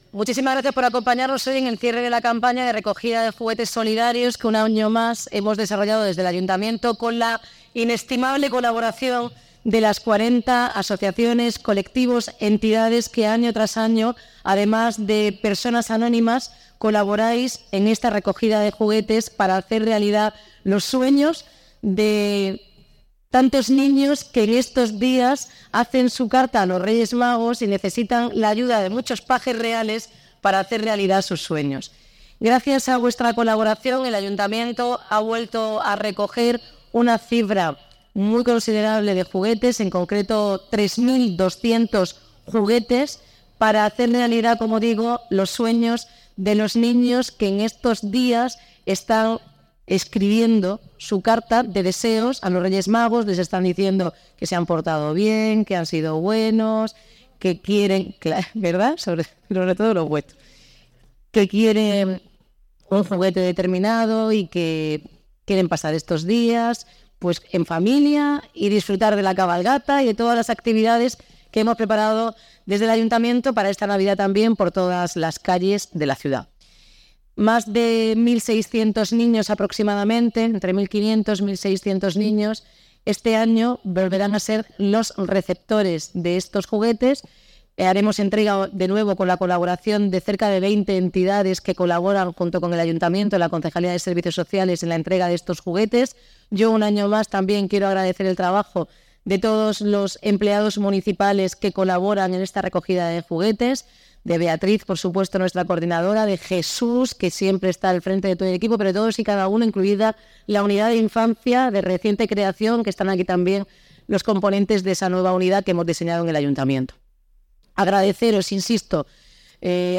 La campaña solidaria Juguetea del Ayuntamiento de Cartagena logra regalos para 1.500 menores para que nadie se quede sin regalo estas fiestas. El cierre de la recogida de juguetes se ha llevado a cabo este viernes, 22 de diciembre, en la plaza Luis Ruipérez, junto al Palacio de Deportes.